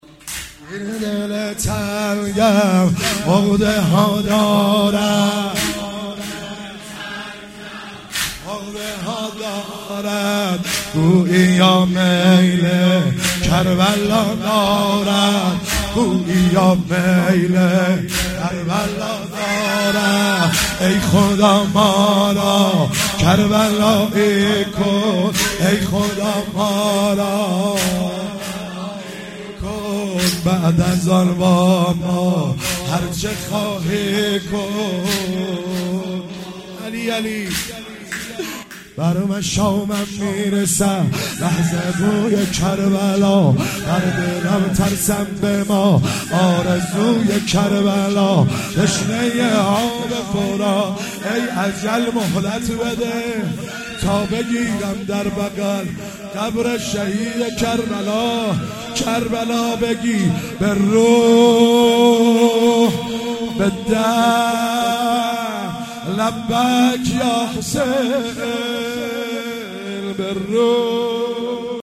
هفتگی 5 آبان96 - واحد - این دل تنگم عقده ها دارد